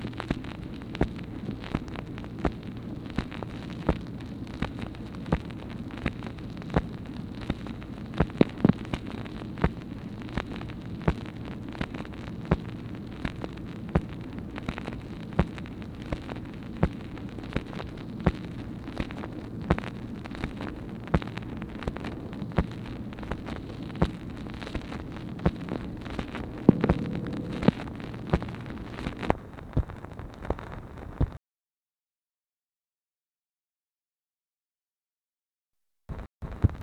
MACHINE NOISE, May 2, 1964
Secret White House Tapes | Lyndon B. Johnson Presidency